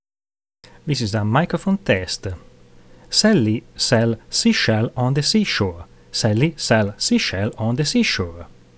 FocusRite iTrack SOLO - test
Now, included with the kit was the microphone that is advertised as an iTrack CM25S.
Quality is, as far as I am concerned, more than adequate for the price paid.
It sounds OK to me.